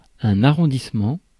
Ääntäminen
IPA: [a.ʁɔ̃.dis.mɑ̃]